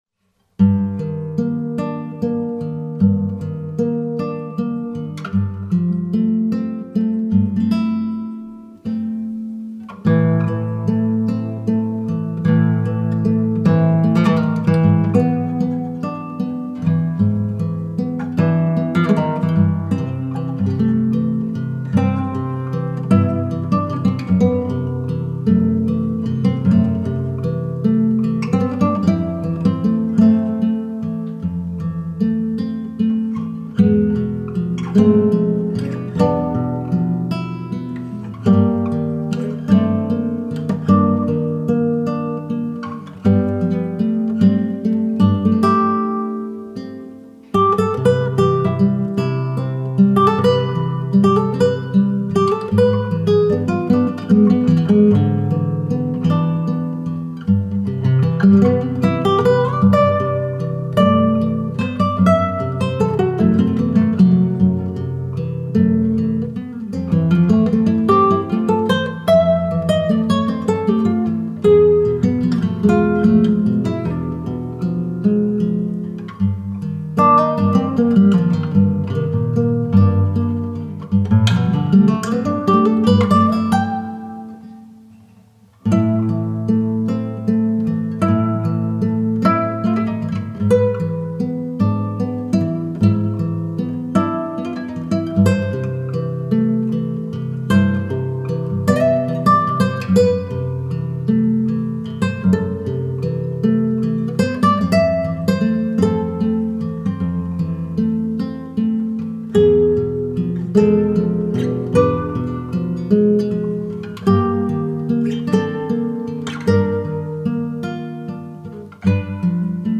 سبک آرامش بخش , موسیقی بی کلام